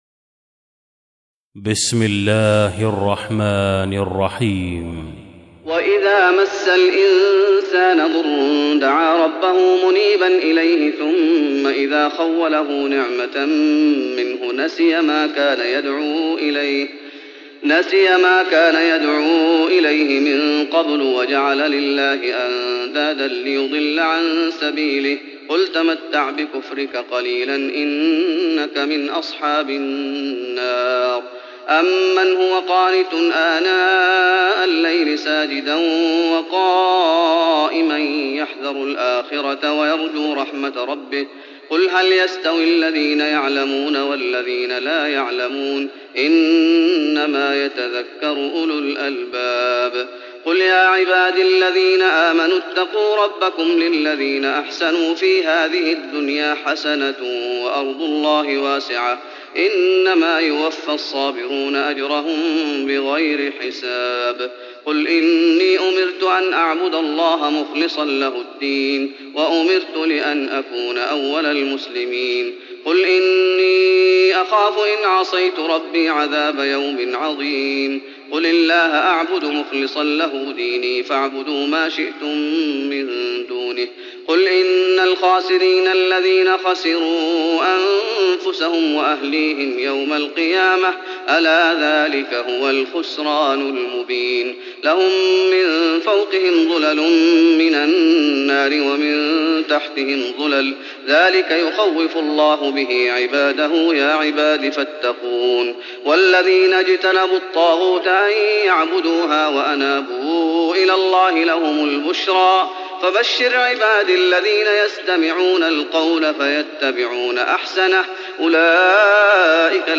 تراويح رمضان 1415هـ من سورة الزمر (8-75) Taraweeh Ramadan 1415H from Surah Az-Zumar > تراويح الشيخ محمد أيوب بالنبوي 1415 🕌 > التراويح - تلاوات الحرمين